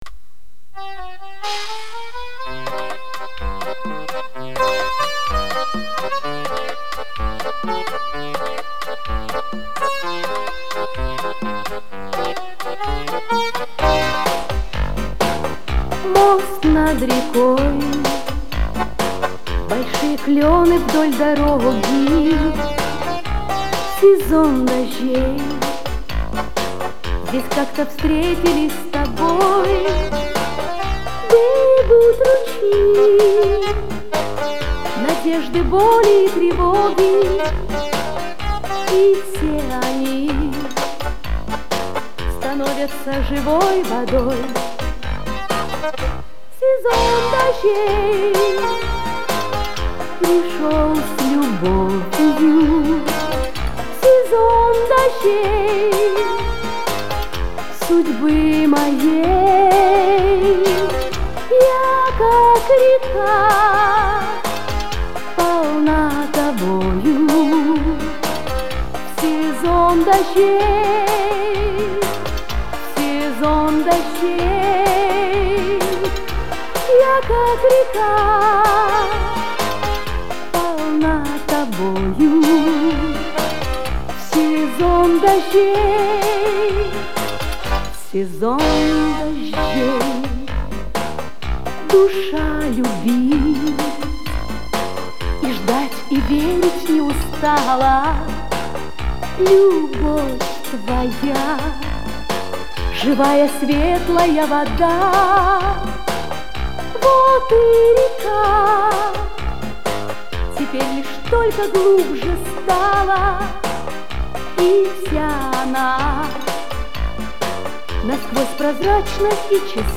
с магнитофона
Это любительская запись.